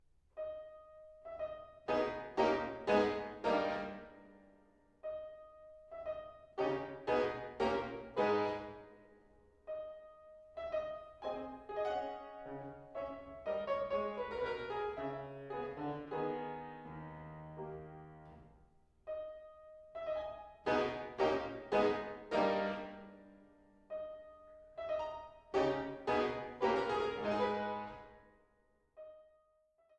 Fortepiano und Clavichord